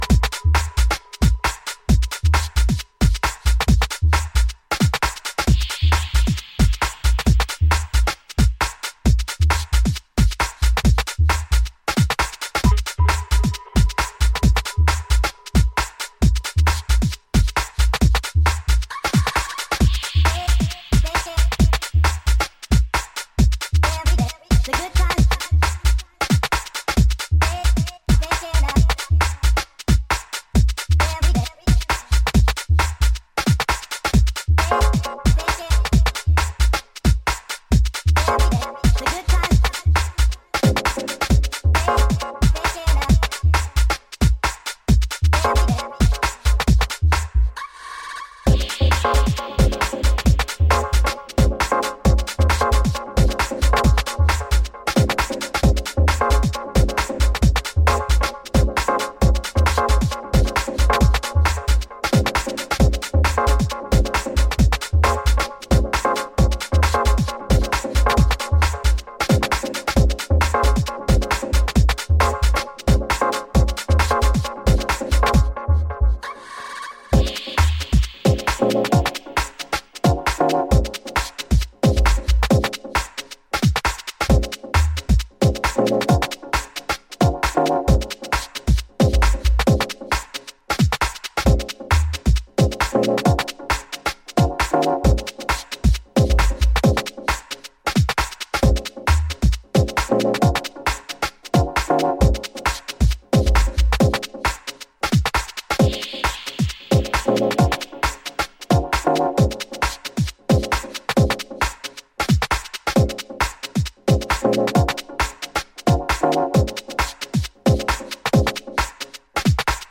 ハウシーなリフがダブテクノ的なエフェクトで存在感を発揮した
ディープでファットな低音が気持ち良い、バッチリ世界水準のフロアチューンが揃う傑作。